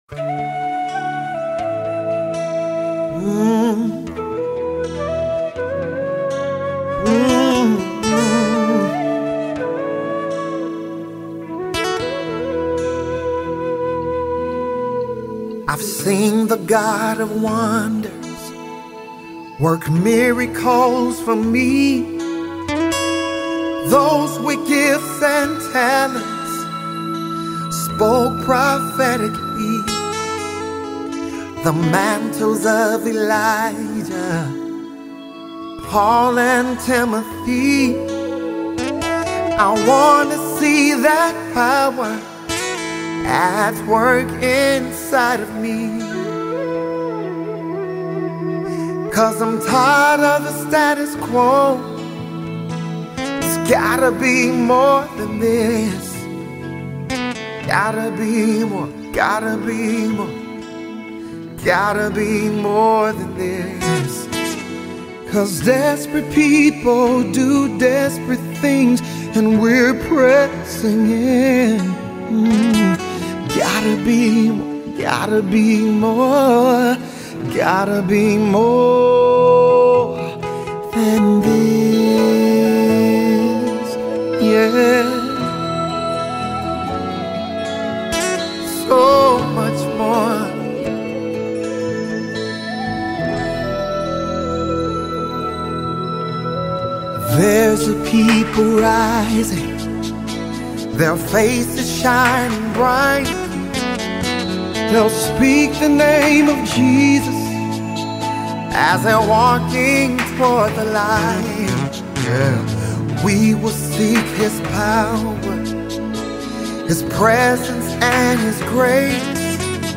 Christian gospel